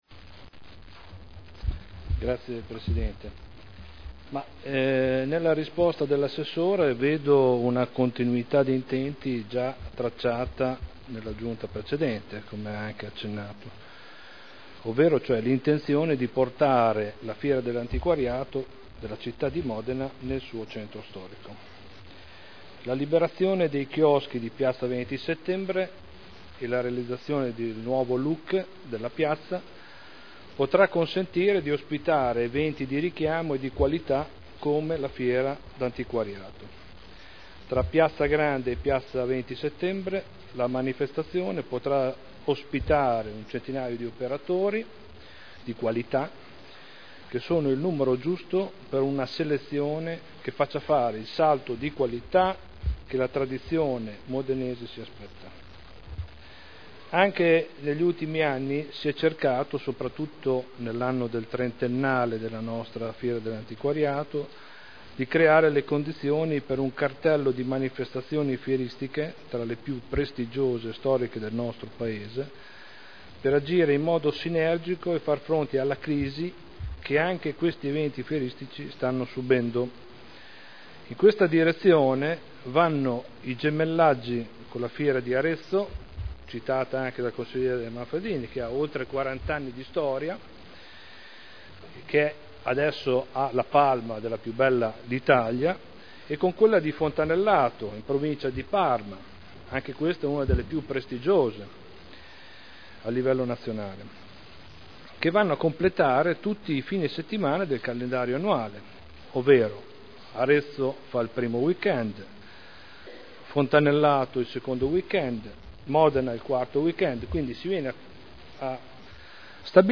Stefano Prampolini — Sito Audio Consiglio Comunale